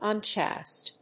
音節un・chaste 発音記号・読み方
/`ʌntʃéɪst(米国英語)/